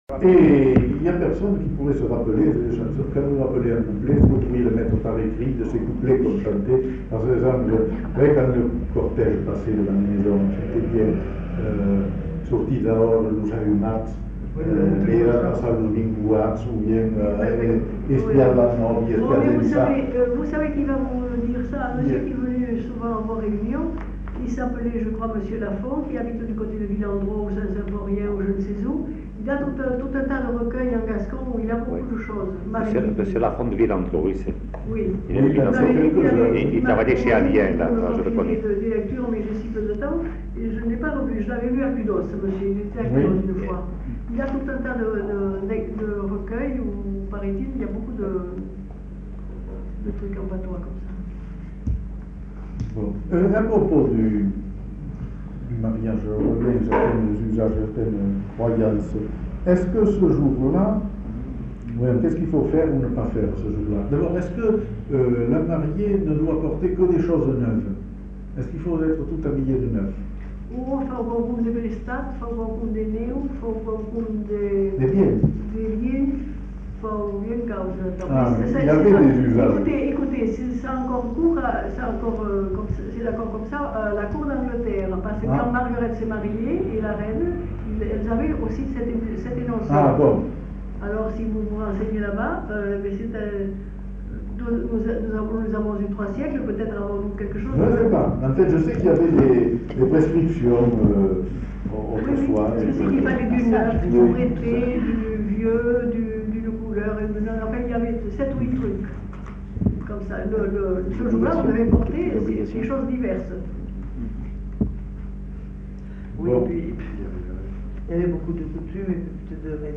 Lieu : Bazas
Genre : témoignage thématique